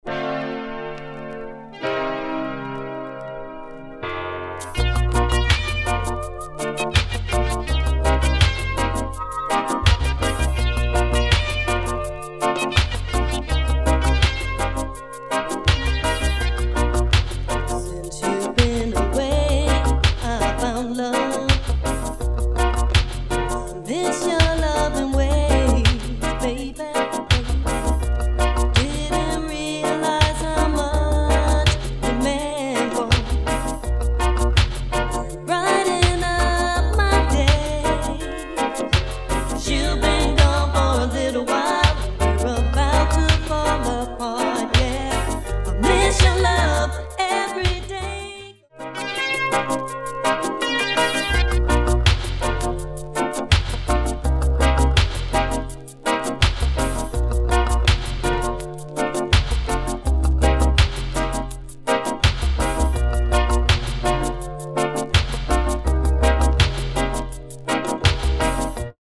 Instrumental Dub